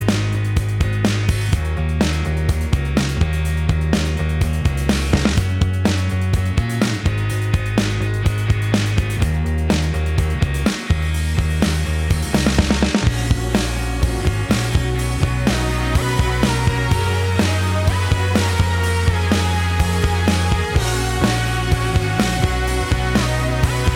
No Lead Guitar Pop